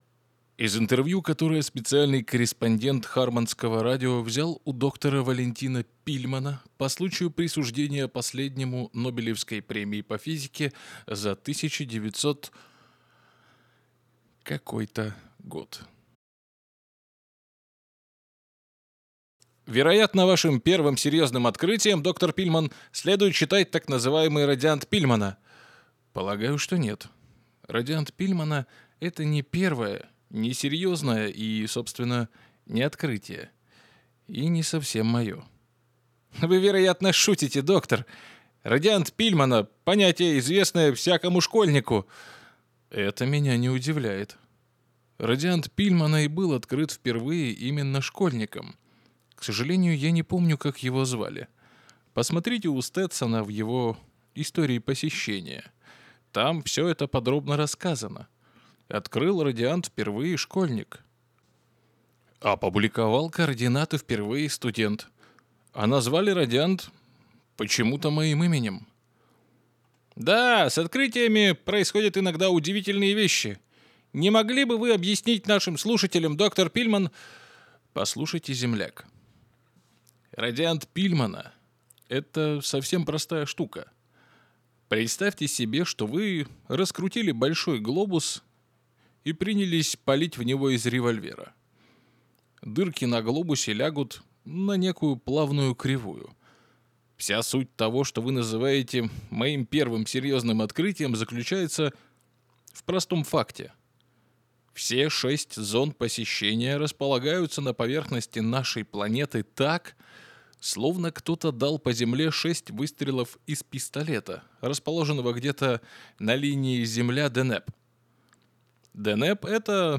Муж, Аудиокнига/Средний
Работаю в Reaper'е, микрофон - Fifine AM8 (динамический, проверенный временем и тоннами текста)